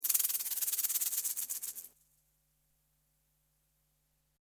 Serpiente de cascabel
Sonidos: Animales Reptiles